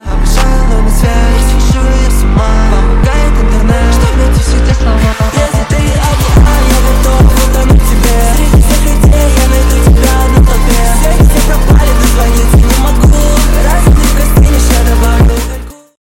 • Качество: 128, Stereo
мужской голос
громкие
русский рэп